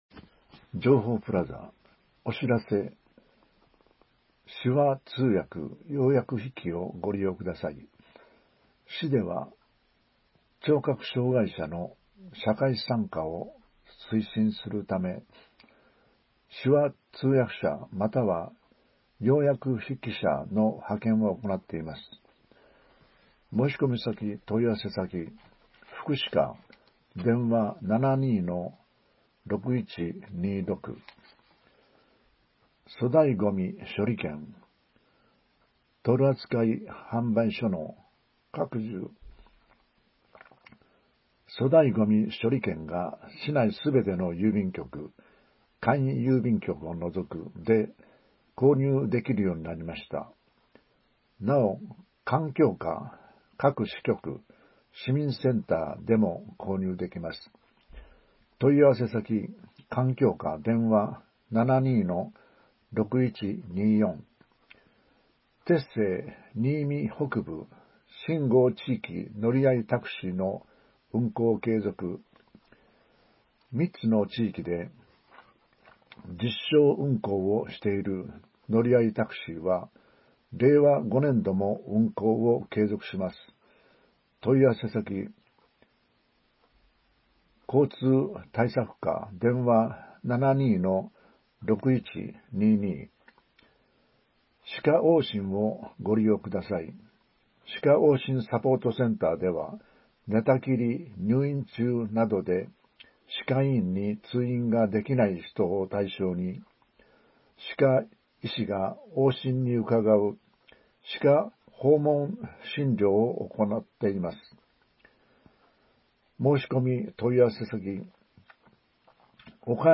声の市報にいみの会から、声の市報４月号を提供いただきました。
市報にいみ４月号の概要を音声でお伝えします。